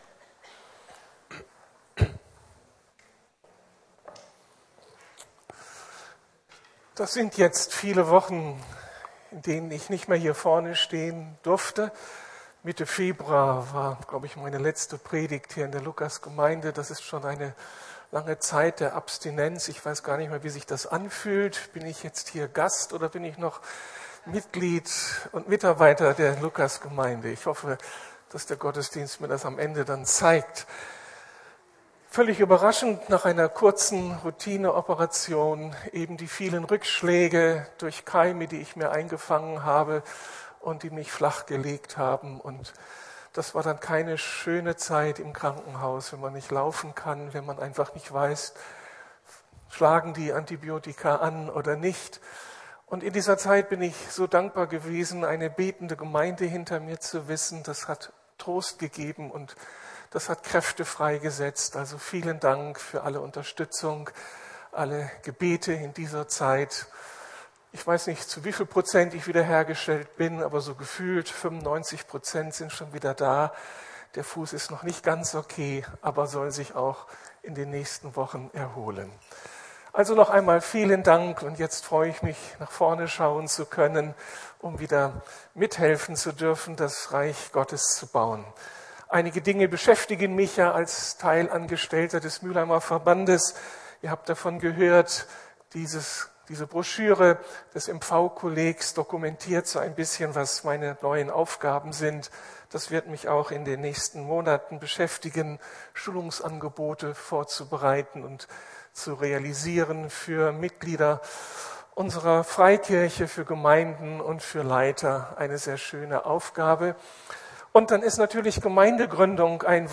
Wenn Christen leidenschaftlich werden ~ Predigten der LUKAS GEMEINDE Podcast